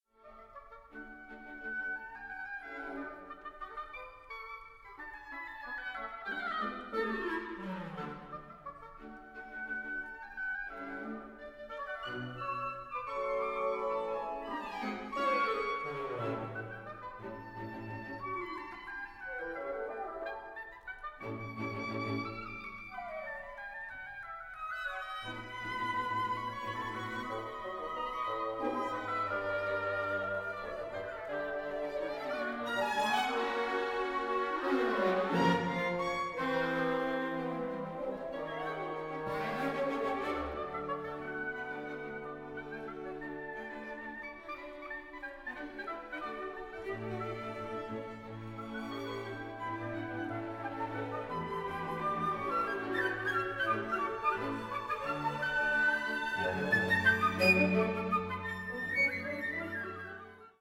SWR Sinfonieorchester Baden-Baden und Freiburg
François-Xavier Roth, conductor
2 Scherzo. Munter
Recorded 9-11 September 2014 (Symphonia domestica) and 6 March 2015 (Metamorphosen) at Konzerthaus Freiburg, Germany